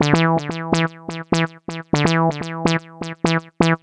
cch_synth_loop_funky_wet_125_D.wav